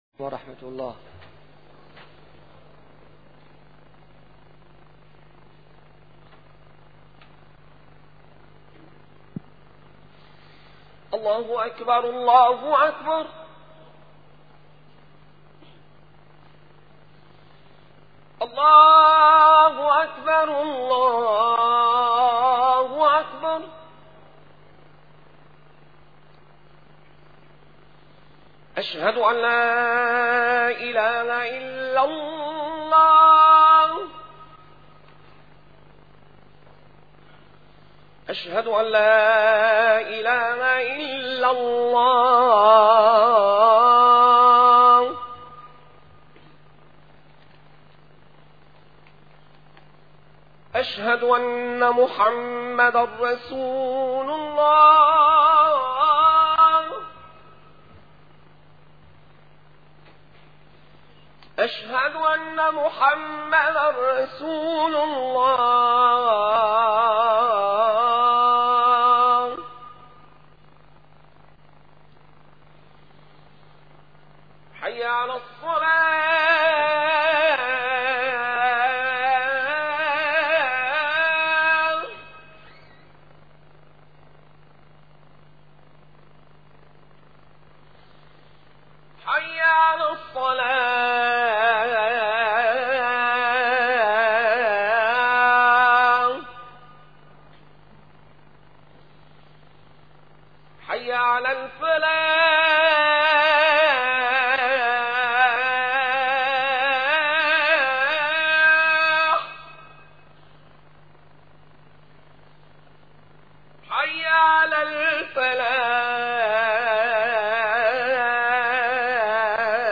- الخطب - محطة بين يدي شعبان